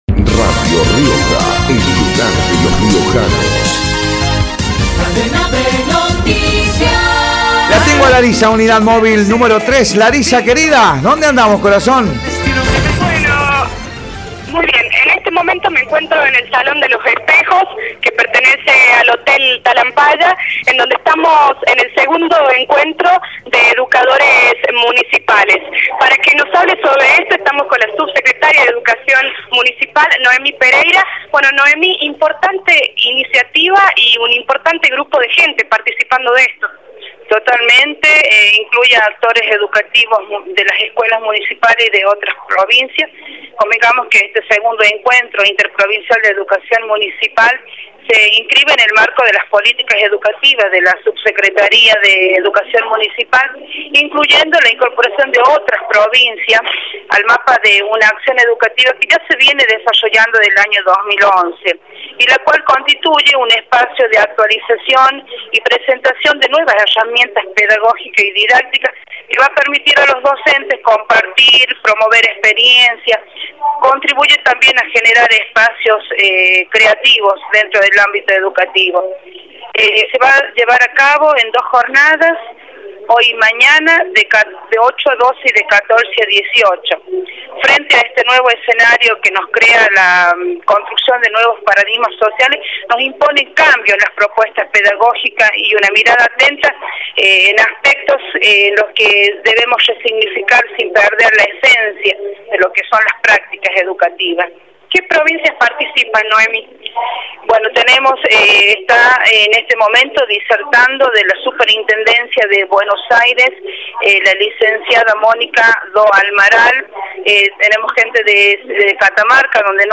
Informe del Encuentro de Educadores Municipales por Radio Rioja
El encuentro, que finaliza mañana, se desarrolla en el Salón de los Espejos del Hotel Talampaya, y el acto inaugural fue presidido por el viceintendente Armando Molina, el secretario de gobierno Oscar Luna, subsecretarios, concejales, directivos y docentes.
informe-del-encuentro-de-educadores-municipales-por-radio-rioja.mp3